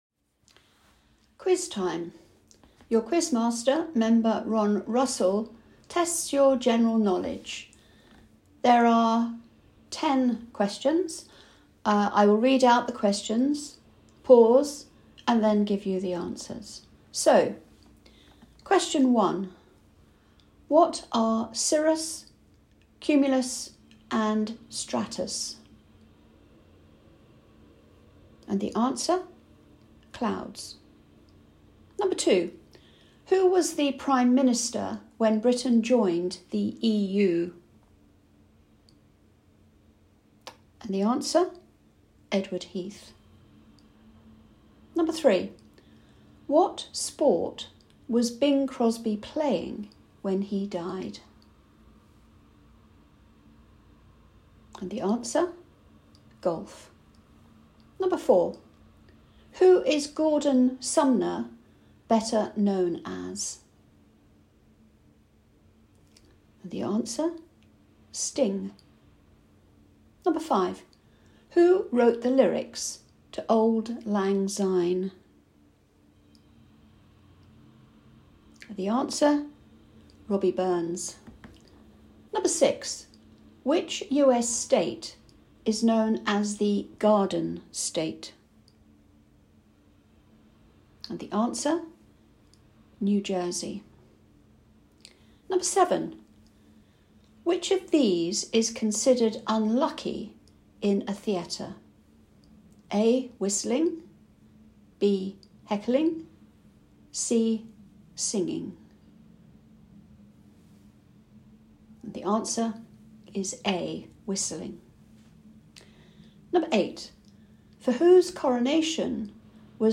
Your quiz master